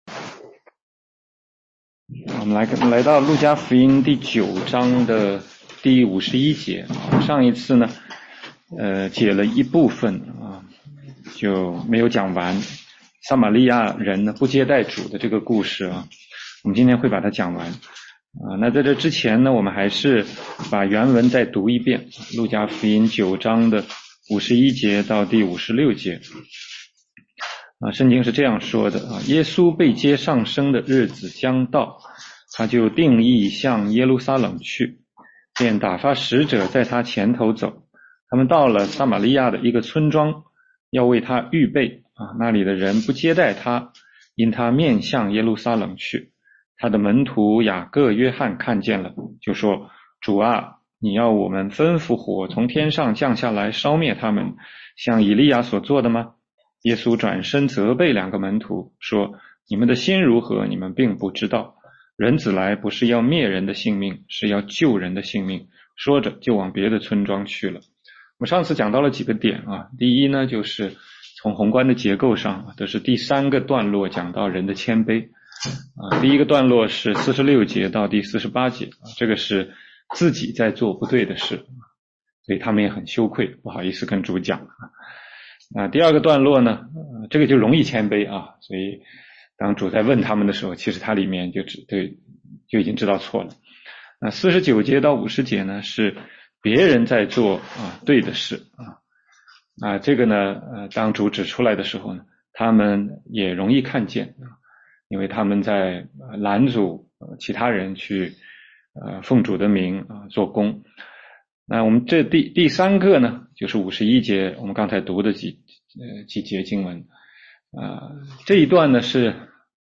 16街讲道录音 - 路加福音9章57-62节：跟随耶稣的代价
全中文查经